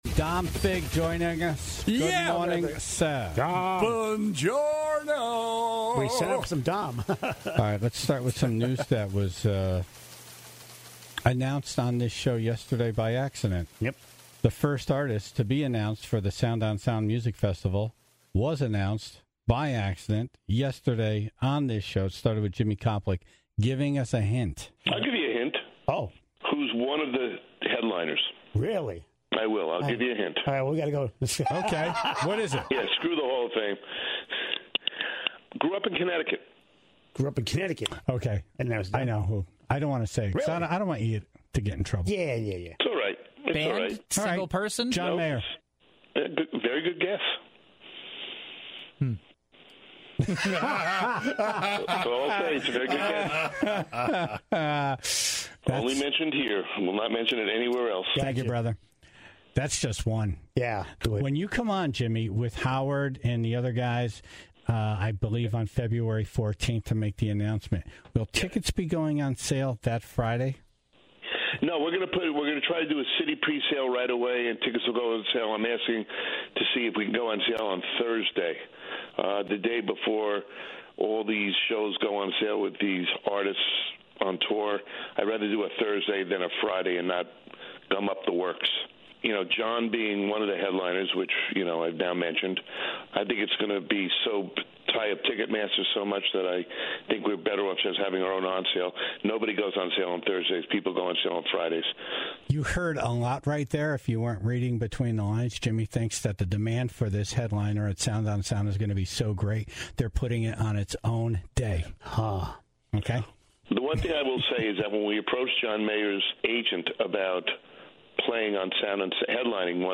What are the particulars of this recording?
in studio to overshare on his relationship with his pets